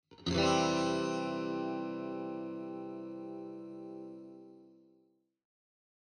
Clean Sound Guitar
Cleanジャラーン(C) 118.27 KB